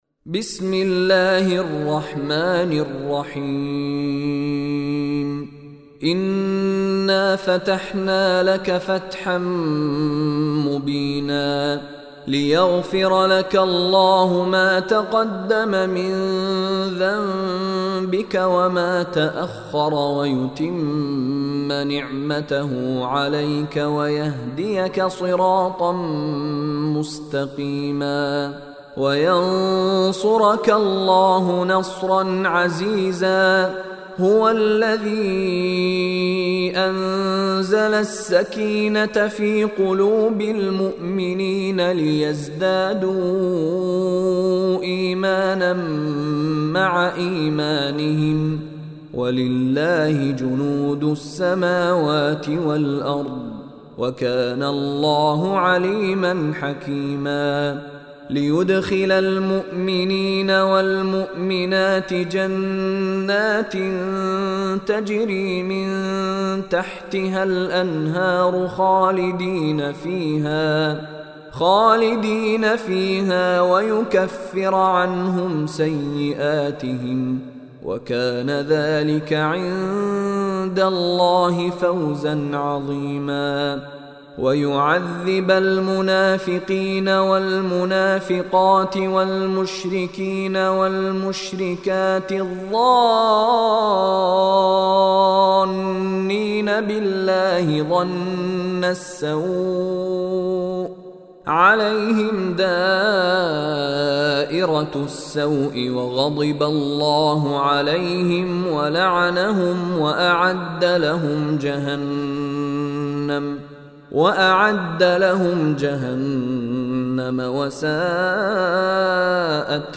Surah Al-Fath is 48 Surah / Chapter of Holy Quran. Listen online and download mp3 tilawat / recitation of Surah Al-Fath in the beautiful voice of Sheikh Mishary Rashid Alafasy.